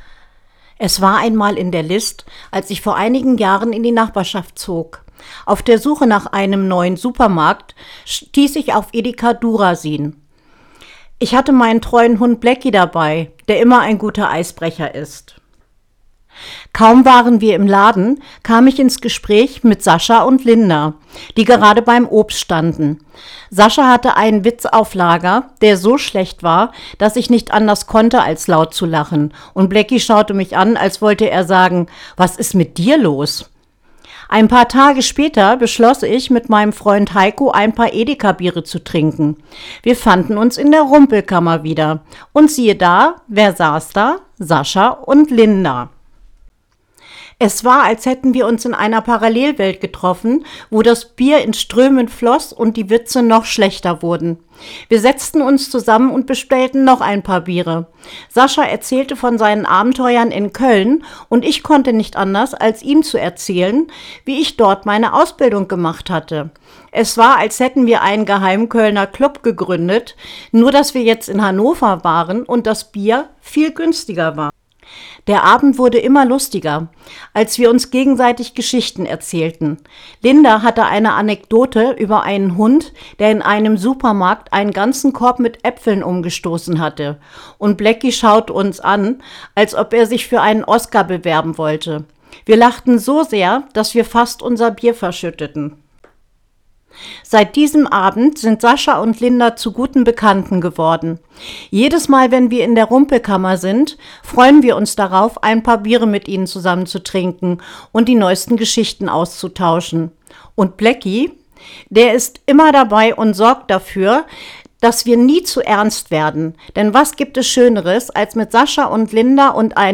Professionelles Studio-Equipment garantiert erstklassige Soundqualität – klar, ausdrucksstark und bereit für dein Projekt.